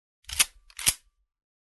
Человек зарядил обрез дробовика